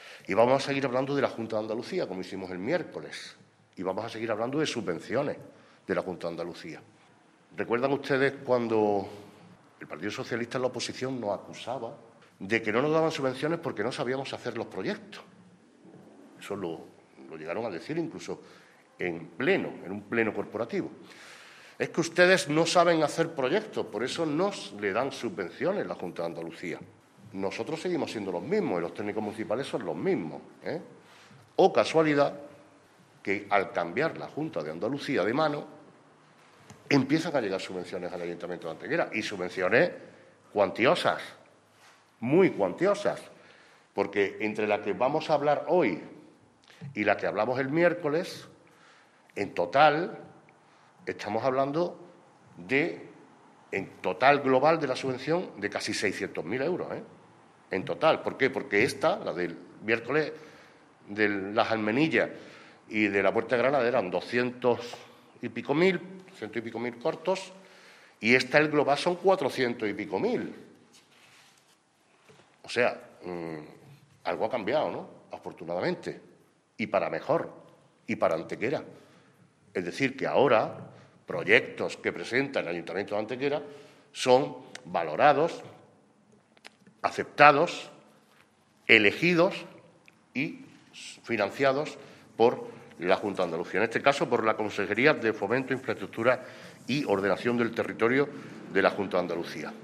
El alcalde de Antequera, Manolo Barón, y el concejal delegado de Obras, José Ramón Carmona, han presentado han informado en la mañana de hoy viernes en rueda de prensa de la puesta en marcha del proyecto para la creación de un nuevo parque homenaje a las mujeres antequeranas que estará enclavado en el área residencial conocida como "Parque Verónica".
Cortes de voz